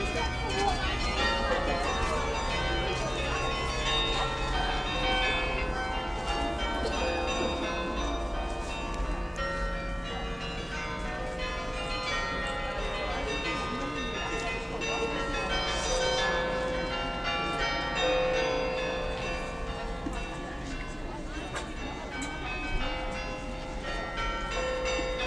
Bournville carol concert
86513-bournville-carol-concert.mp3